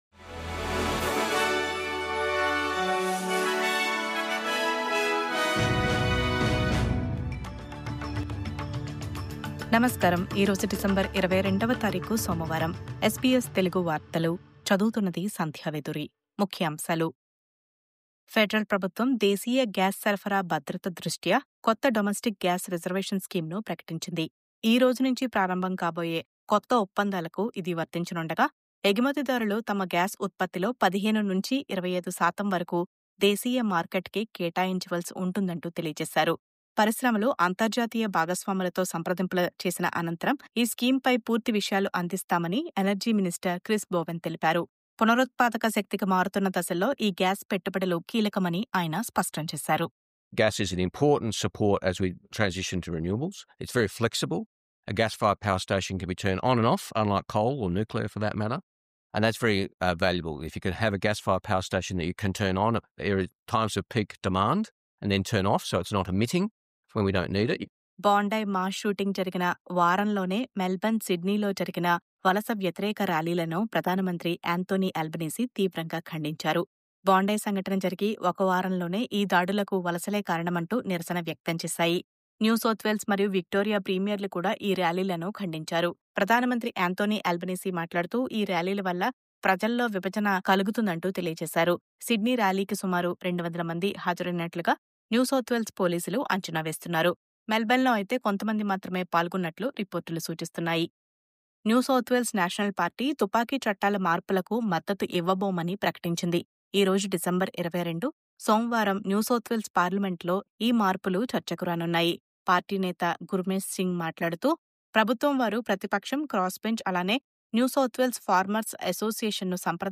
News update: Bondi Mass Shootingలో మృతి చెందినవారి జ్ఞాపకార్ధం దేశమంతా విజిల్స్ నిర్వహించారు..